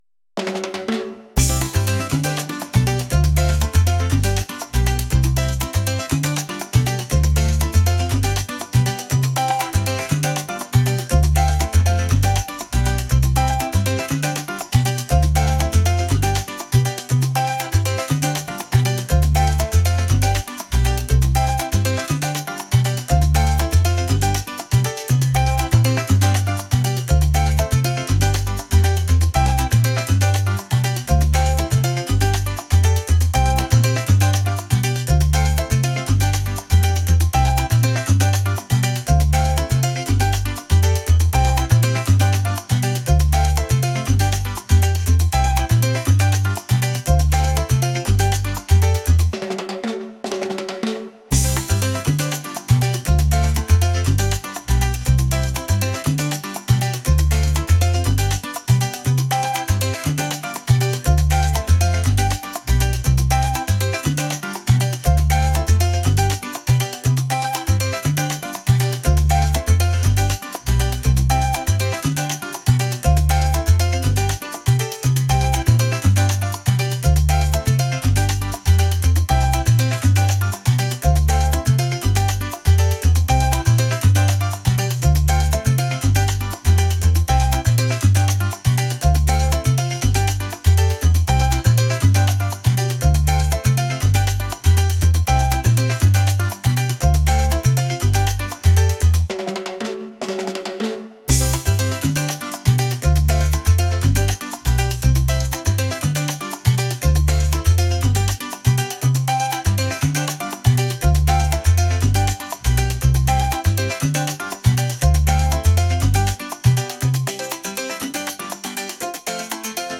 energetic | latin | upbeat